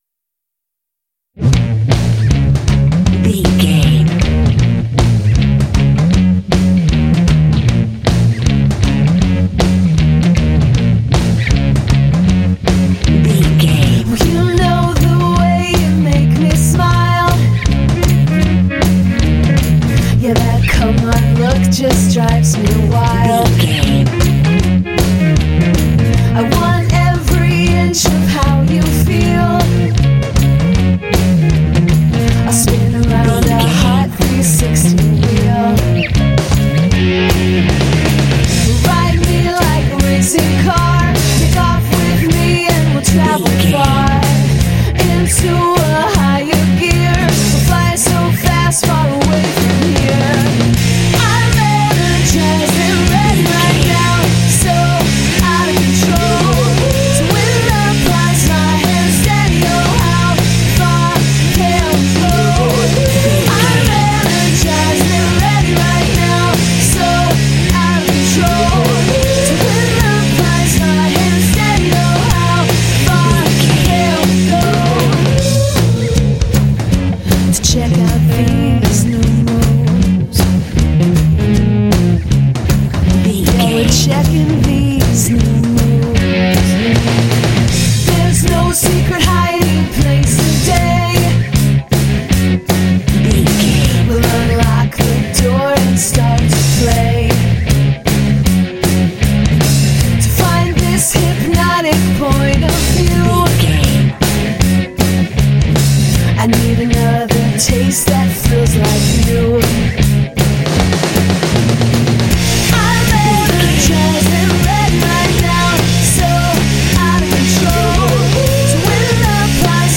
Aeolian/Minor
B♭
energetic
hard
sultry
aggressive
drums
electric guitar
bass guitar
vocals
alternative rock
indie